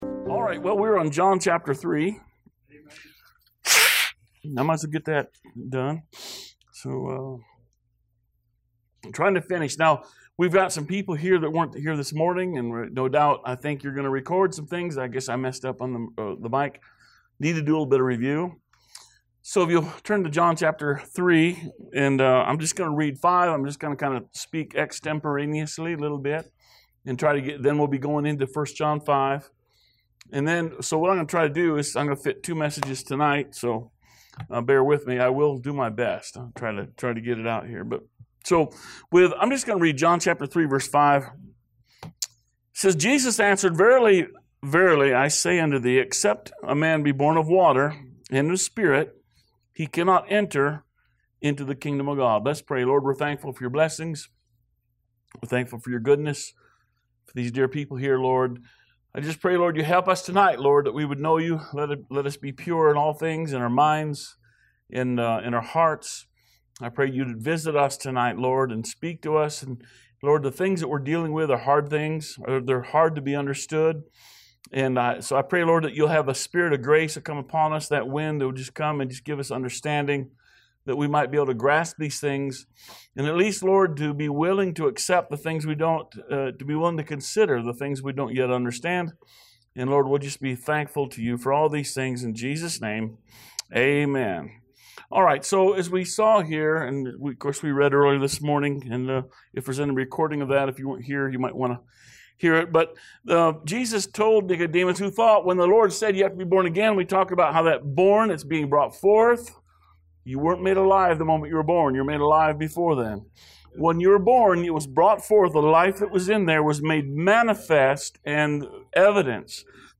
Sermons | Liberty Baptist Church